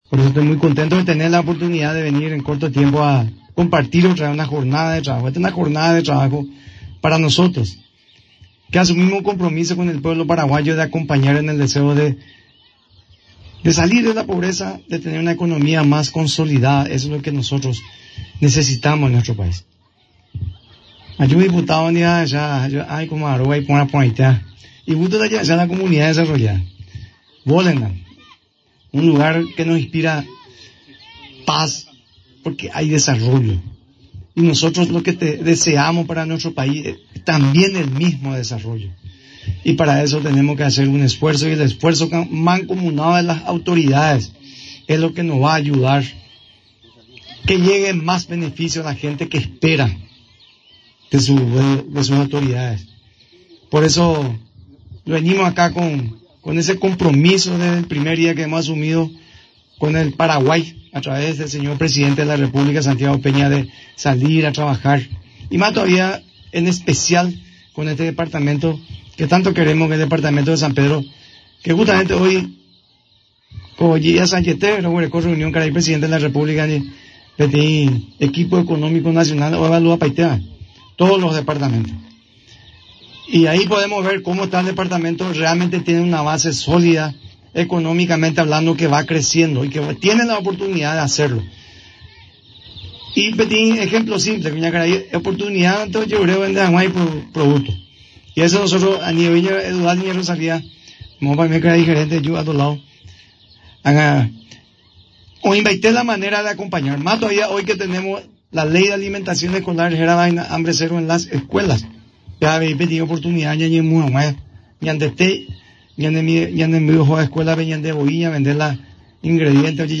NOTA: DR. CARLOS GIMÉNEZ-MINISTRO DEL MAG